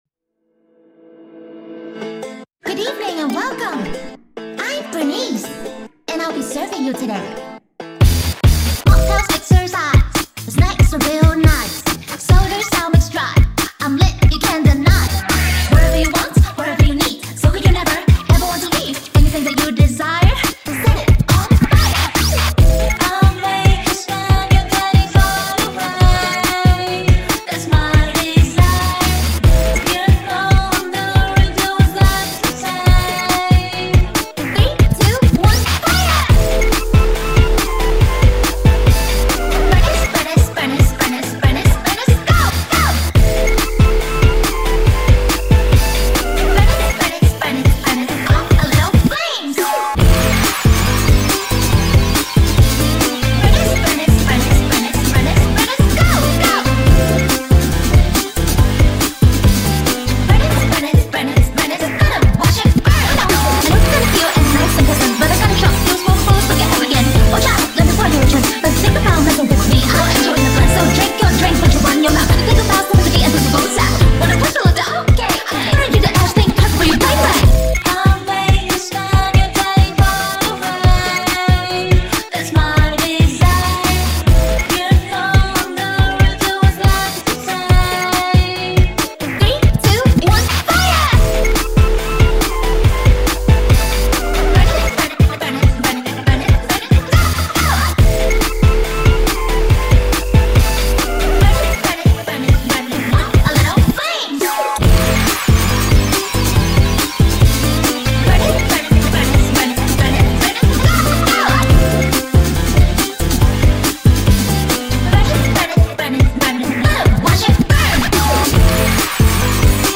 Music / Game Music
Music - Mixes